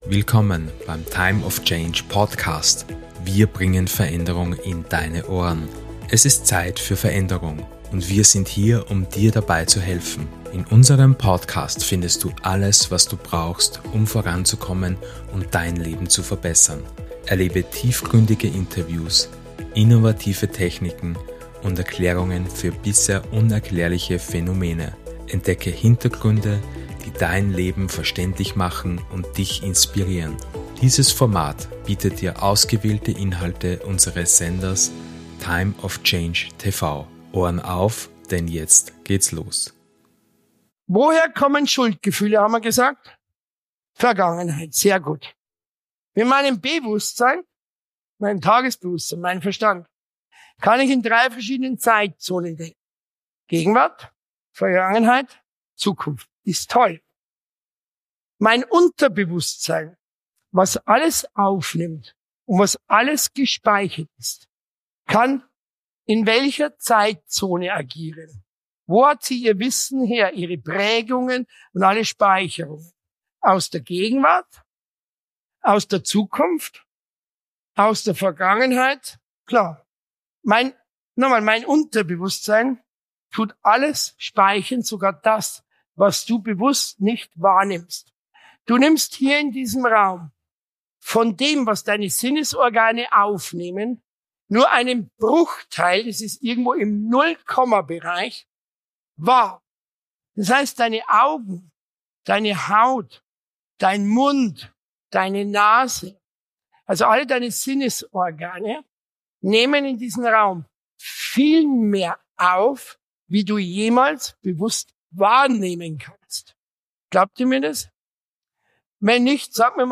In diesem siebten Teil des "Days of Change" Seminarwochenendes